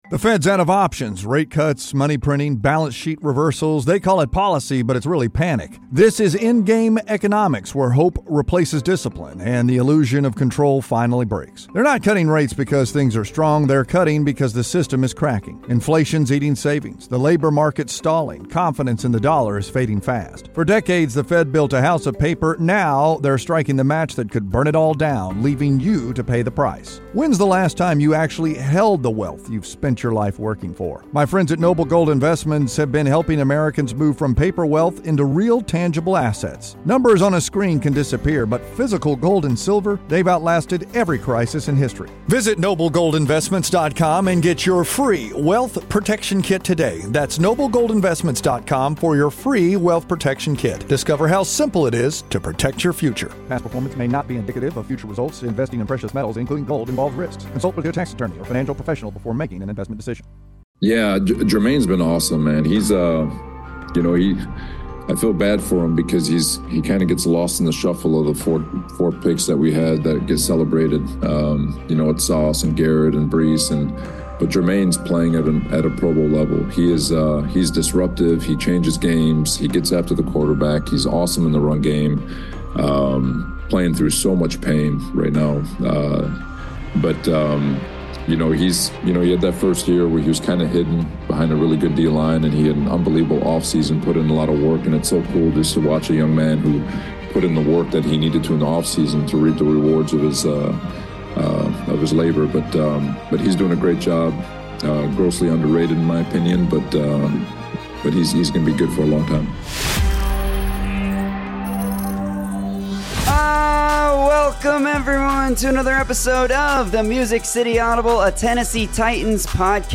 are LIVE to react to the Tennessee Titans trading DT T'Vondre Sweat to the New York Jets for DE Jermaine Johnson. We also hit the Titans cutting C Lloyd Cushenberry and S Xavier Woods, and discuss the Titans upcoming rebrand reveal.